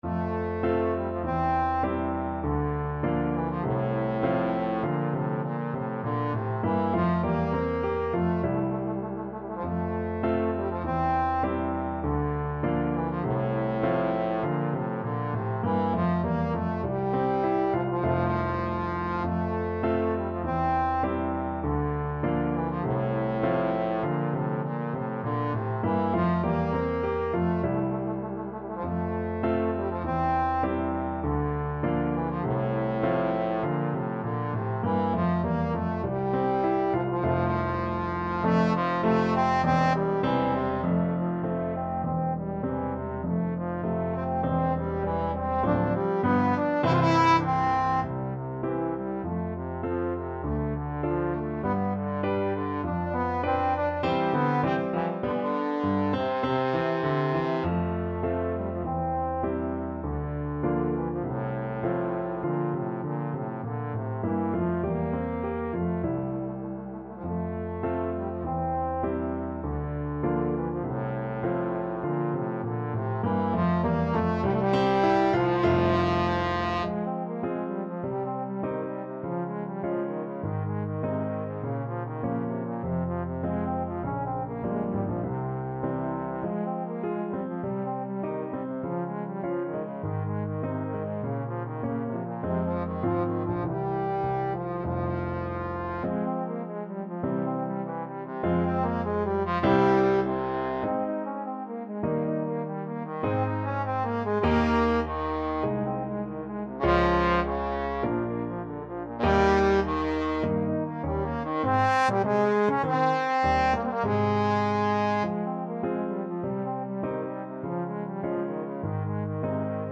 Ab3-E5
2/2 (View more 2/2 Music)
~ = 100 Allegretto
Classical (View more Classical Trombone Music)